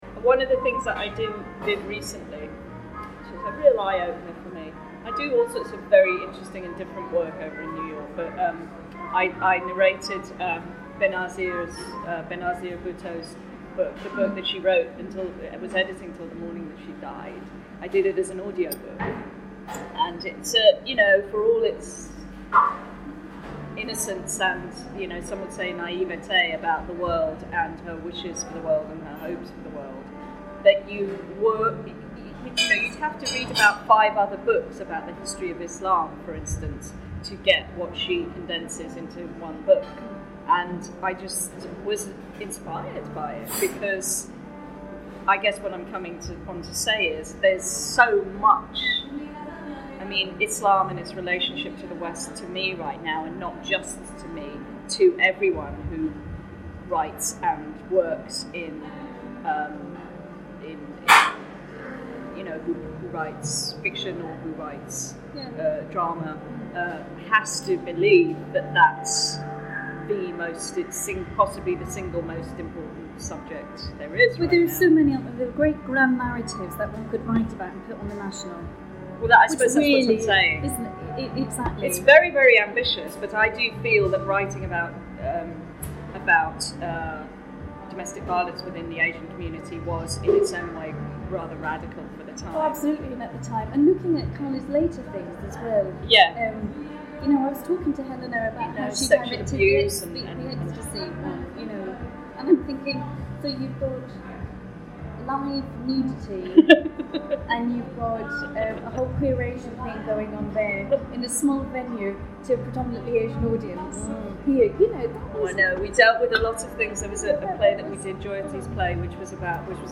Note: this was recorded in a public space, and the audio quality is not as good, so it might be better to listen with headphones.